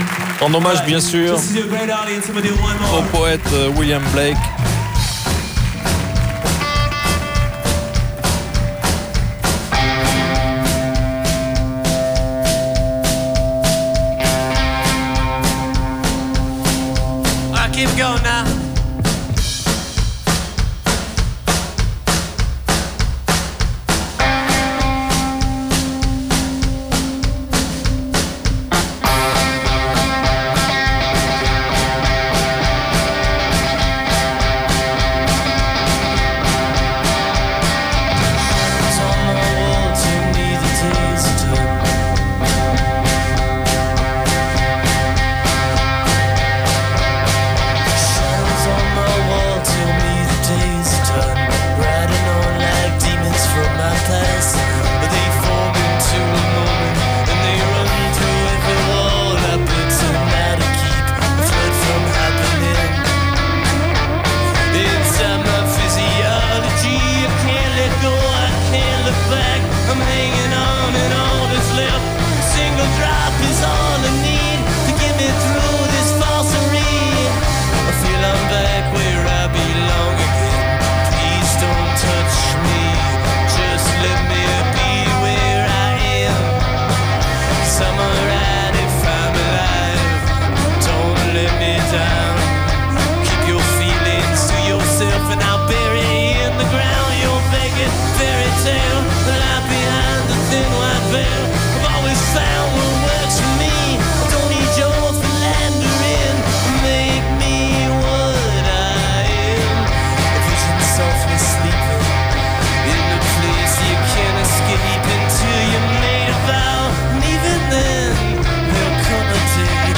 enregistrée le 17/03/2008  au Studio 105